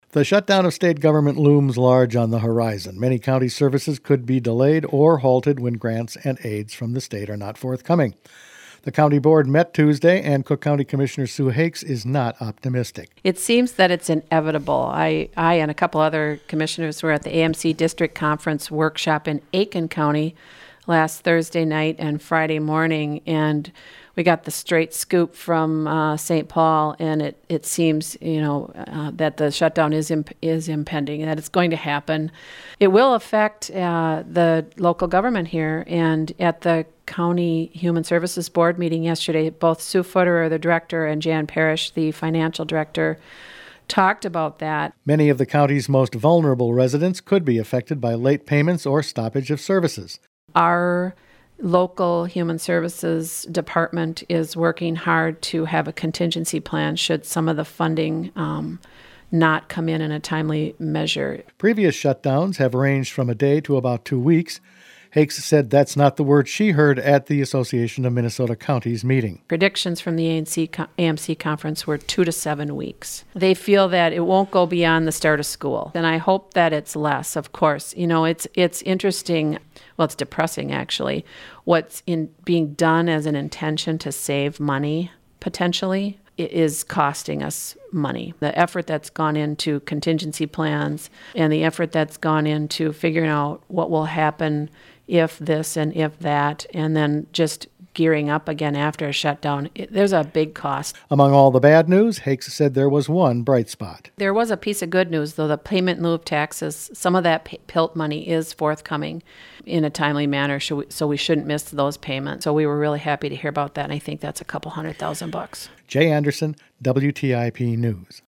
has this report.